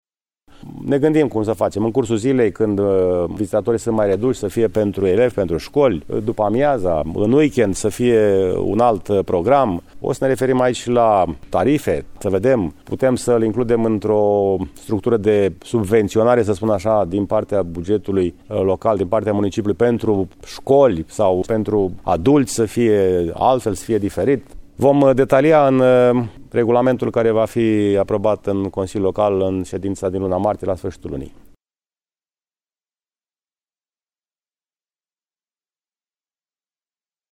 Pe lângă turiști, municipalitatea vrea ca elevii din județul Brașov să aibă ore de științe aici, în timpul săptămânii, conform unui program ce trebuie să fie stabilit împreună cu școlile, după cum a explicat primarul municipiului Brașov, George Scripcaru: